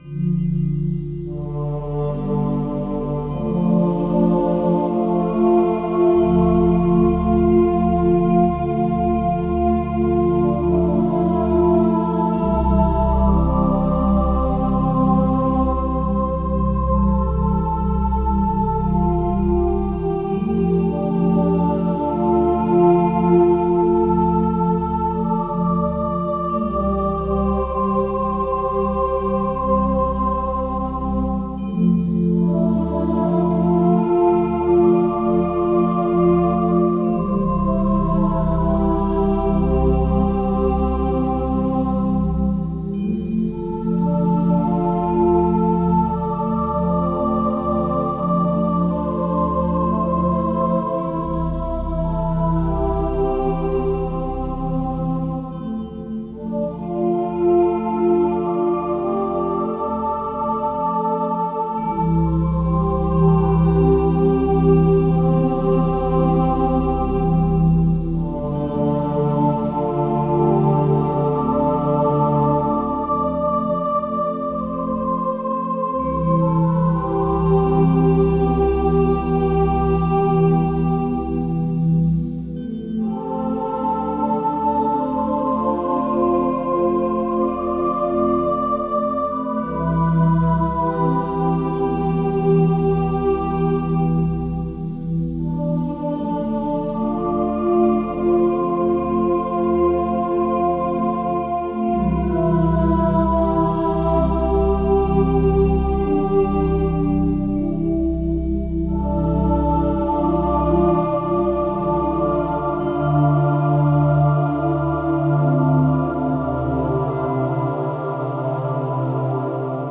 Spherical Soundtrack.